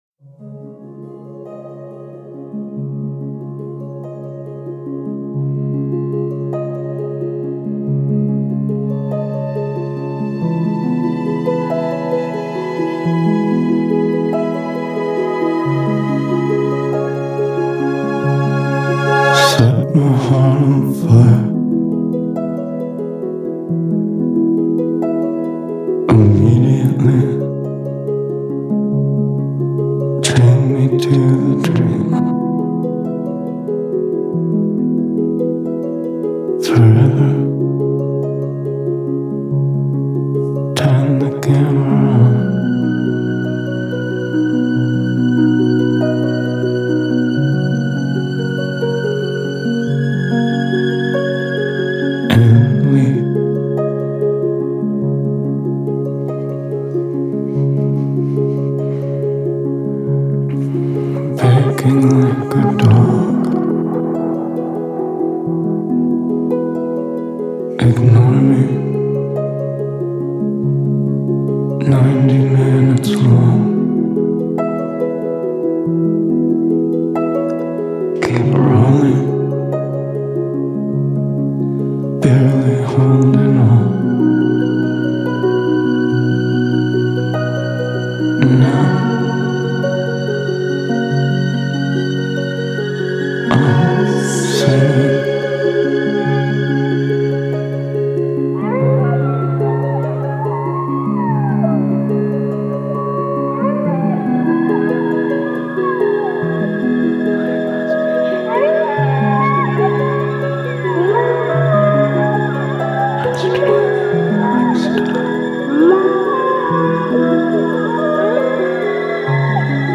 Genre : Alt. Rock